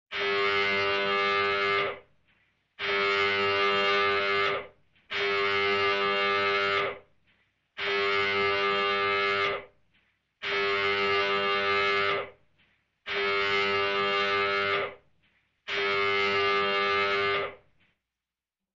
Резкие сирены, сигналы аварийной ситуации и другие звуковые эффекты помогут создать атмосферу напряжения для видео, подкастов или игровых проектов.
Тревожное состояние